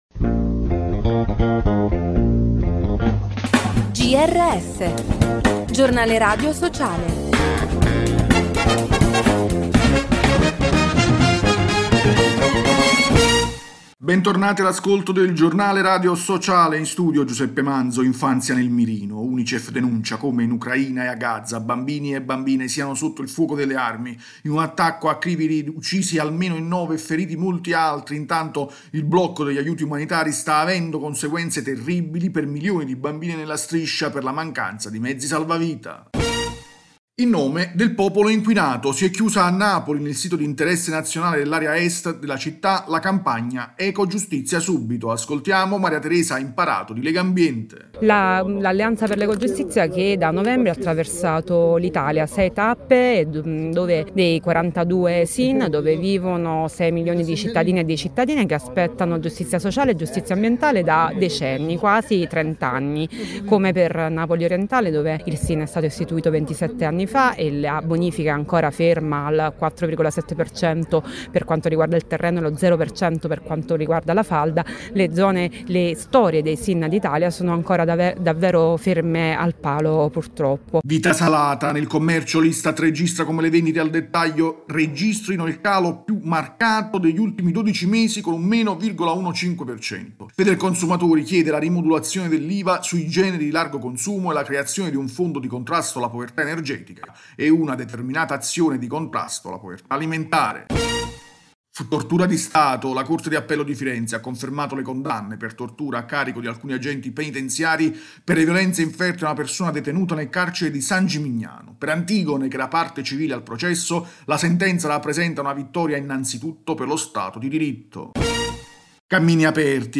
Bentornati all’ascolto del Giornale radio sociale.